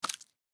sounds / mob / frog / eat2.ogg
eat2.ogg